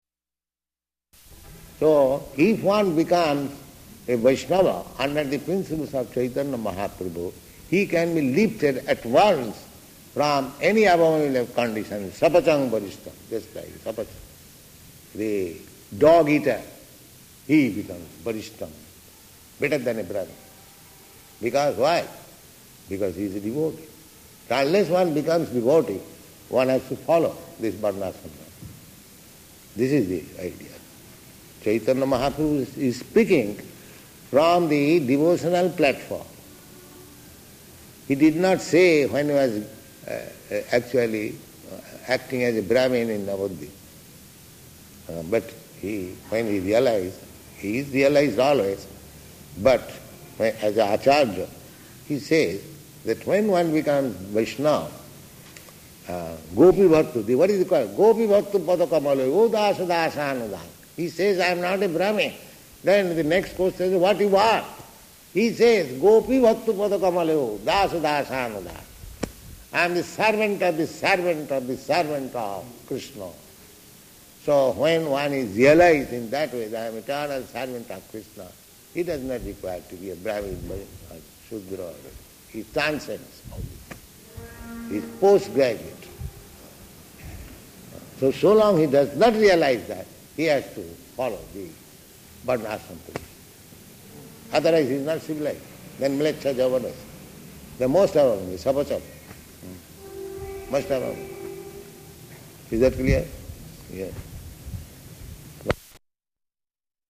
Lecture [partially recorded]
Location: Sydney